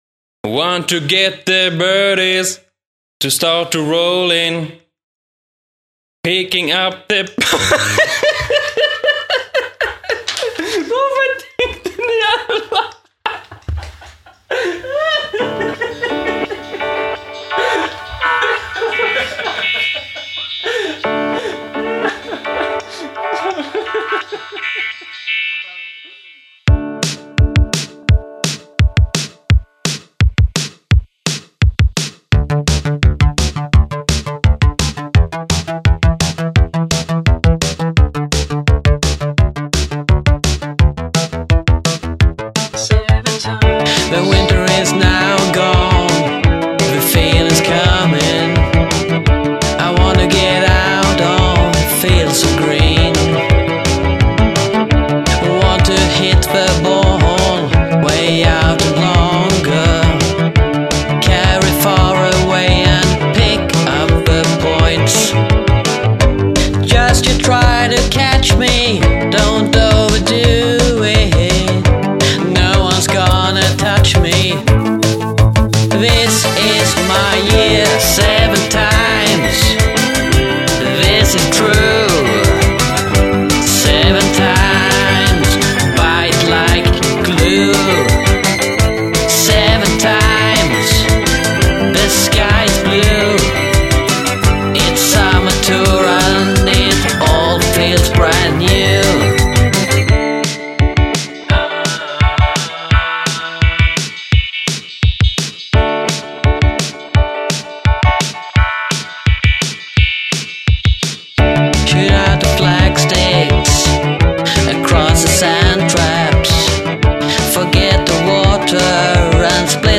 ( Så här illa går det när man skriver en låt och tvingas bakom mic:en, trots att man har duktiga musiker runtom: